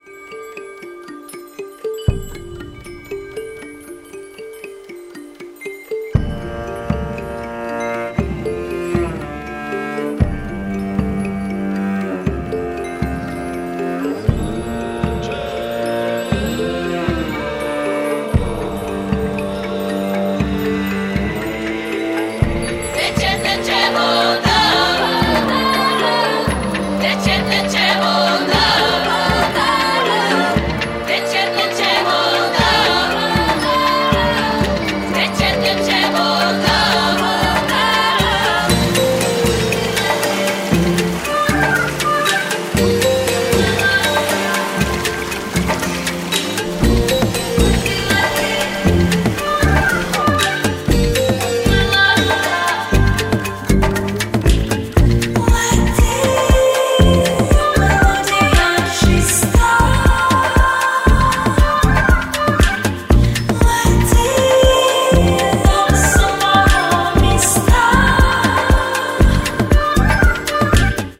• Качество: 192, Stereo
красивые
атмосферные
инструментальные
фолк
волшебные